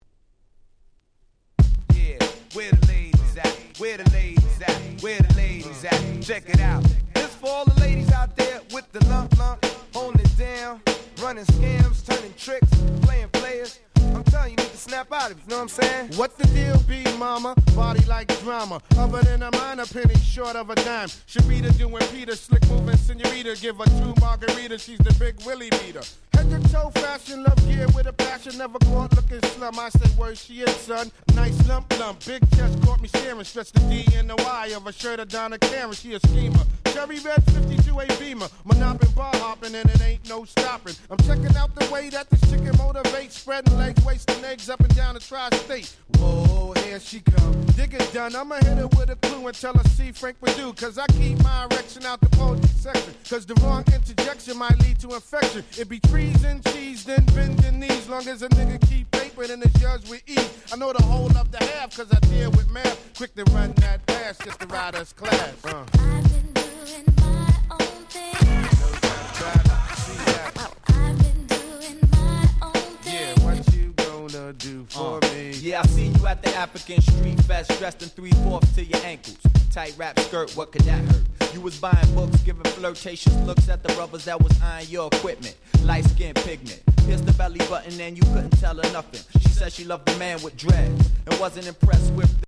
96' Smash Hit Hip Hop !!